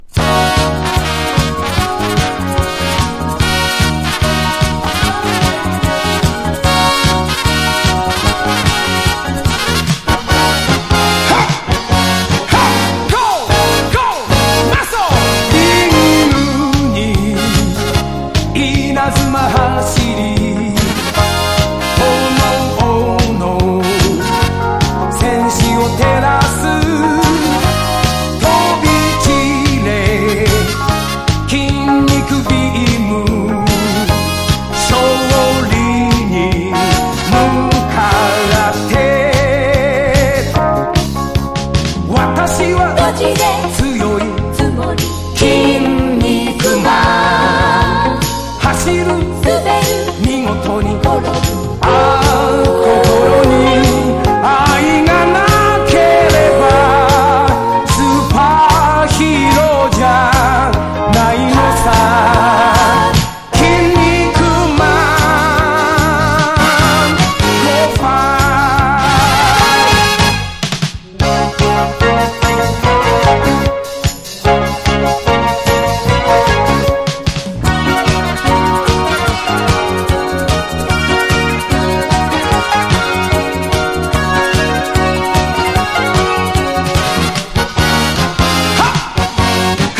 POP# 和モノ / ポピュラー
所によりノイズありますが、リスニング用としては問題く、中古盤として標準的なコンディション。